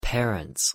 Pronunciation En Parents (audio/mpeg)